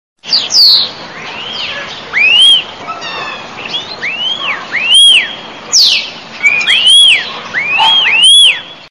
Birds Ringtones